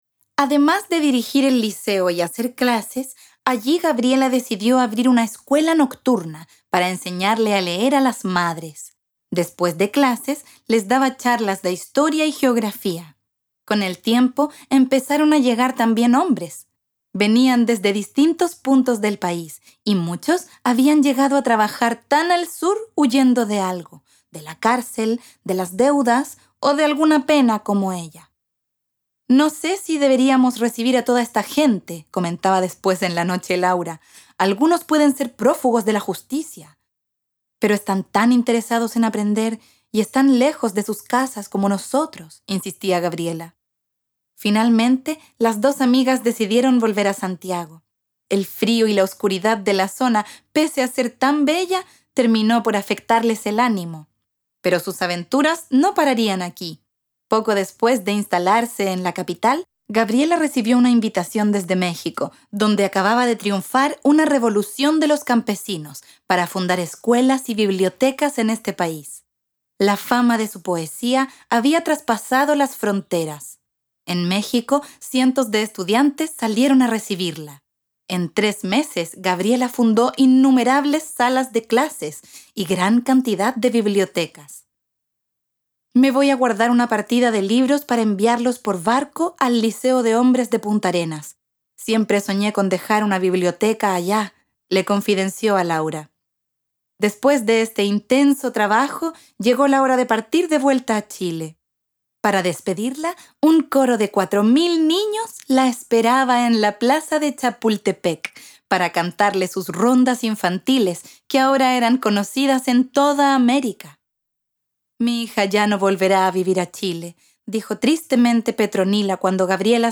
Audiocuento